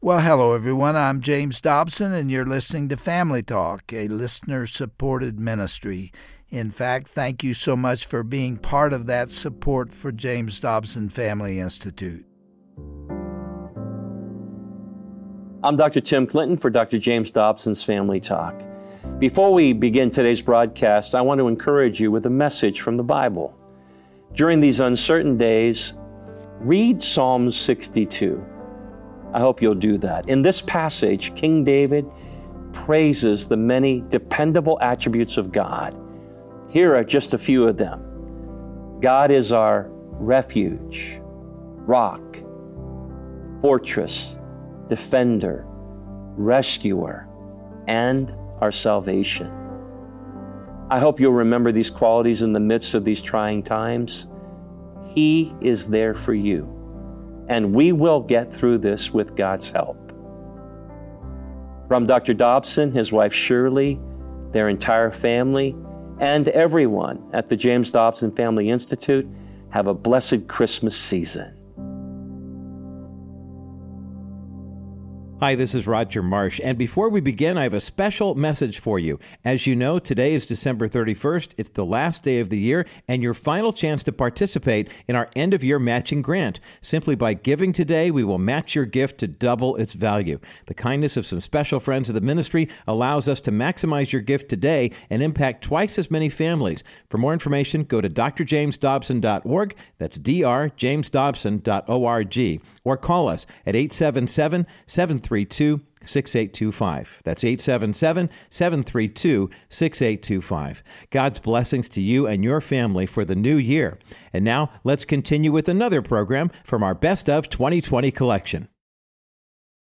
On this edition of Family Talk, Dr. James Dobson continues his interview with Lysa Terkeurst, president of Proverbs 31 Ministries. She explains that our call to forgive stems from God's undeserved grace toward us.